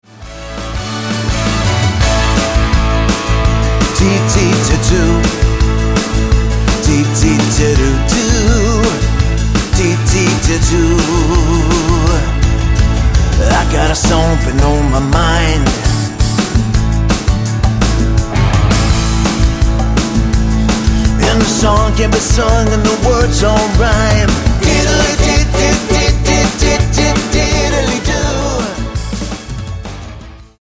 Tonart:A Multifile (kein Sofortdownload.
Die besten Playbacks Instrumentals und Karaoke Versionen .